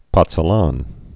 (pŏtsə-län) also poz·zo·la·na (-sə-länə) or poz·zuo·la·na (-swə-)